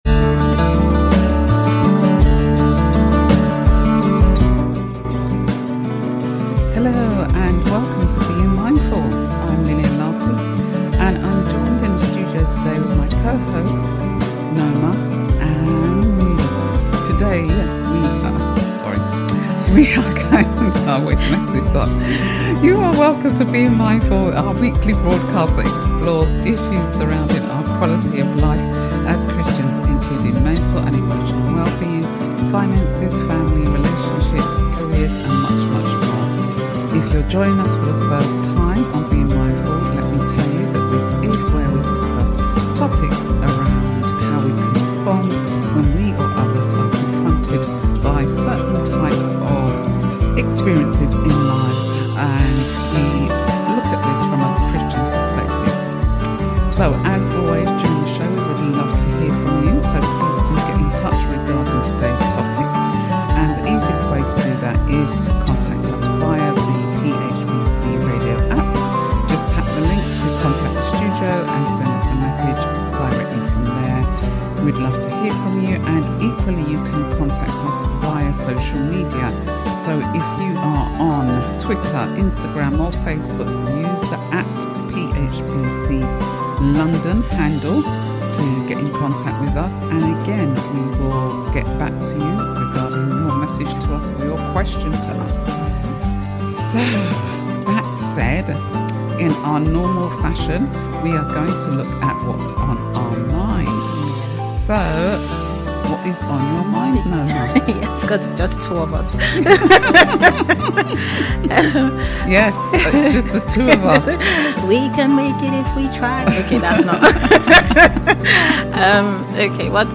Click to listen or download a recording of this live show.